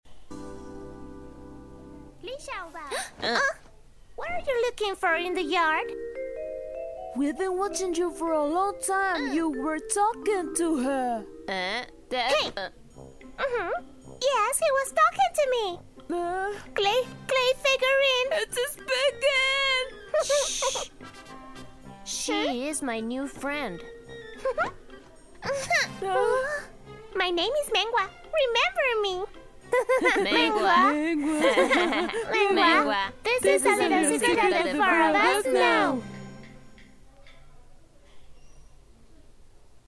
西班牙语配音
• 女西102T 西班牙语女声 童声动画片English Casting_demo 低沉|激情激昂|大气浑厚磁性|沉稳|娓娓道来|科技感|积极向上|时尚活力|神秘性感|调性走心|亲切甜美|感人煽情|素人